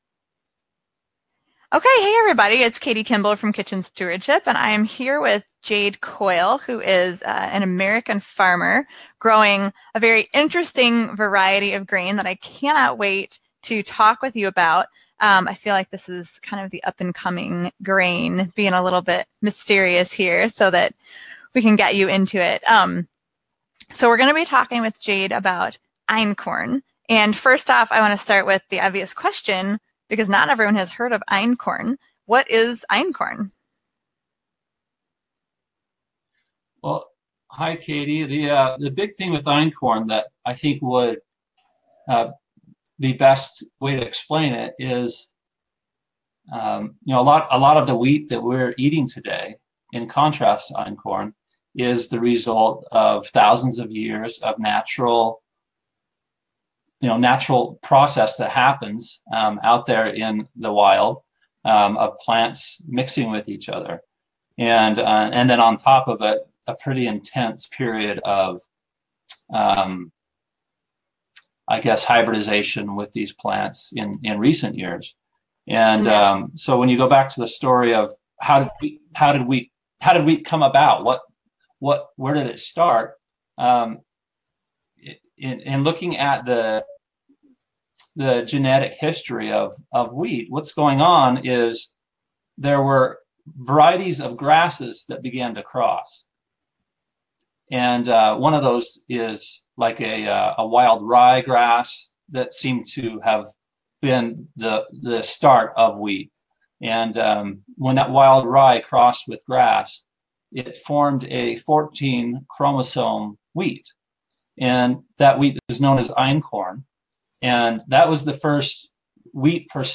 Baking with Einkorn: an Interview with a Farmer & Tips for Use in Whole Wheat Recipes